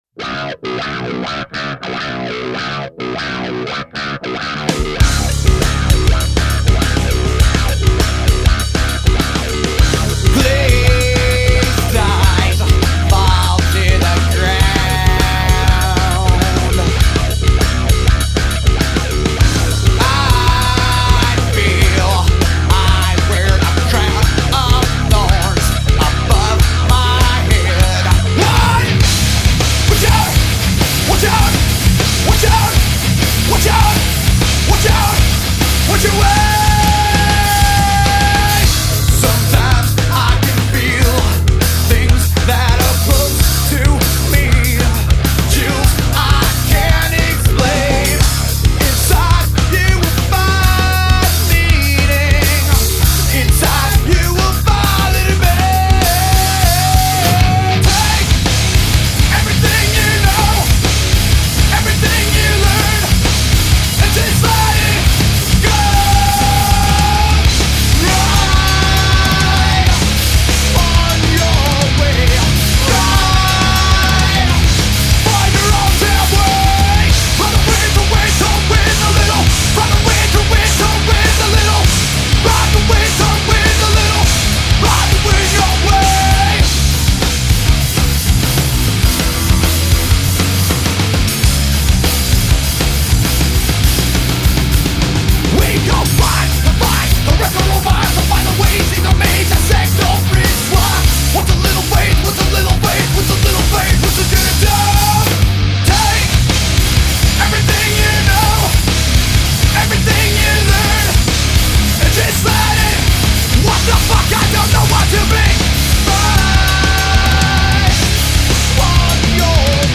Bass and Vocals
Drums